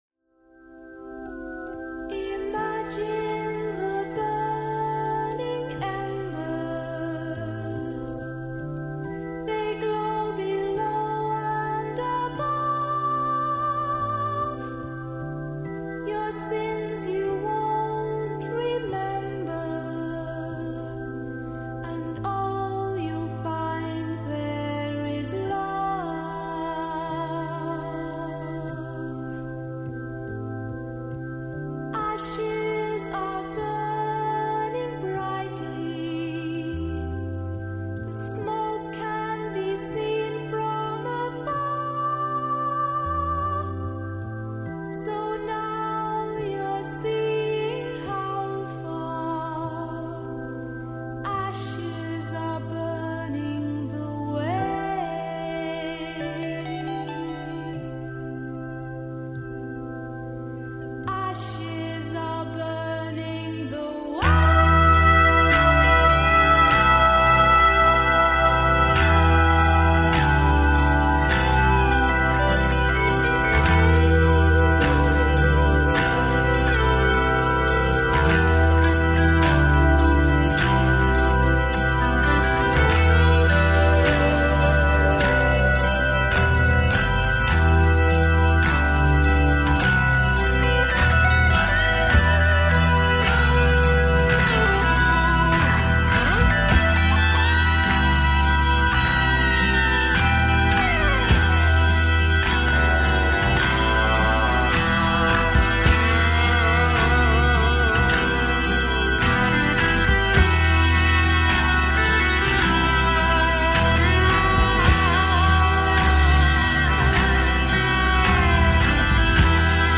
stark och vacker - gråtfärdig!